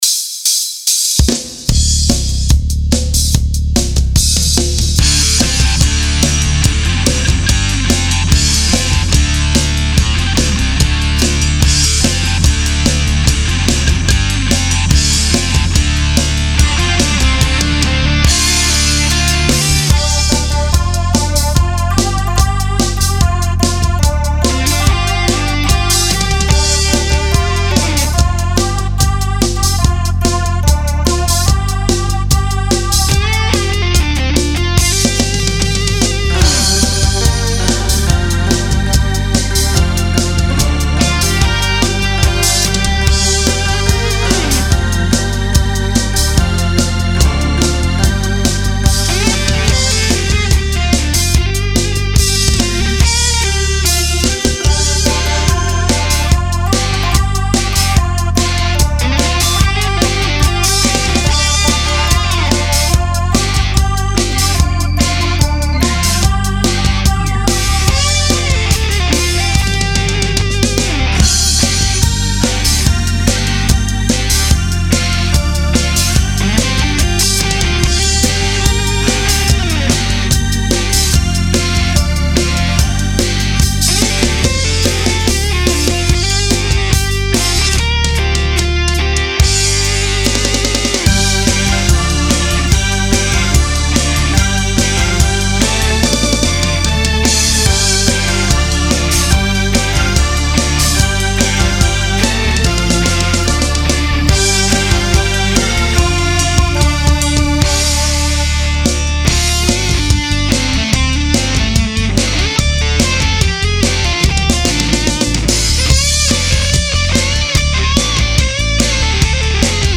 Το τραγούδι είναι γραμμένο στην κλίμακα ΡΕ μινόρε.
Lead & Power Guitar
Ντράμς
Πλήκτρα, Μπάσο